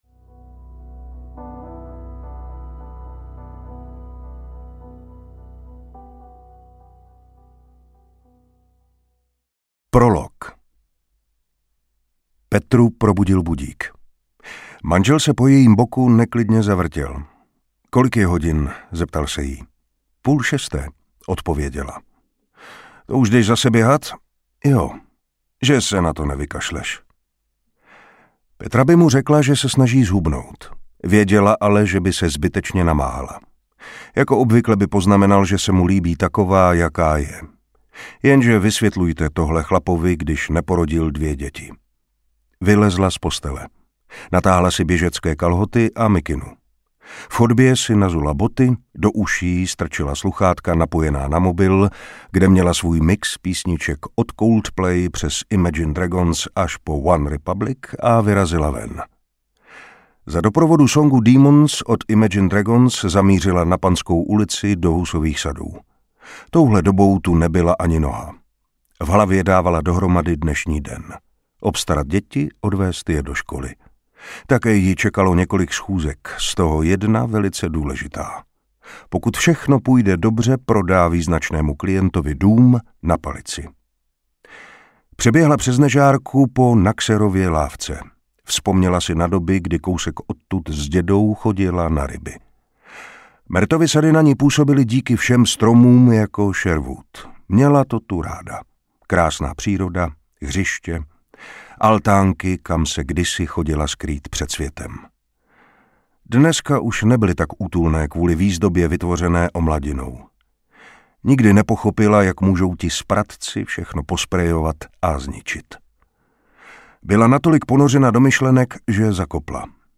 Bez duše audiokniha
Ukázka z knihy
• InterpretMartin Preiss, Kryštof Hádek